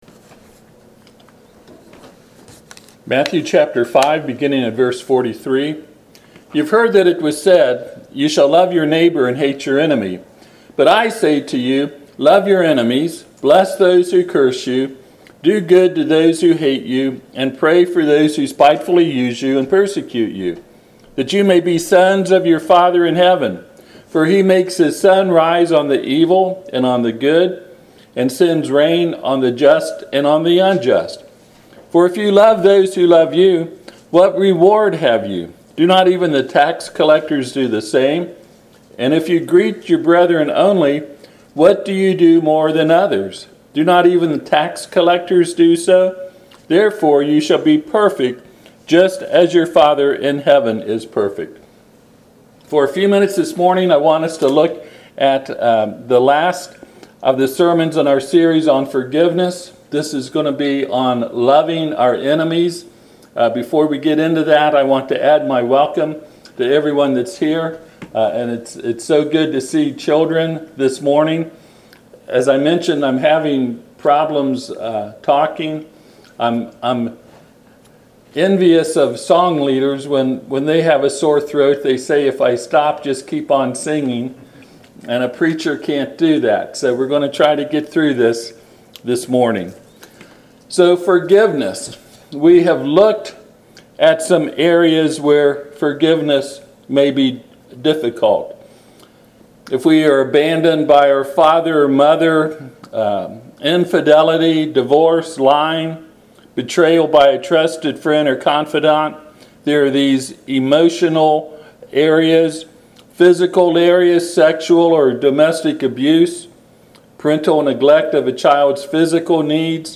Passage: Matthew 5:43-48 Service Type: Sunday AM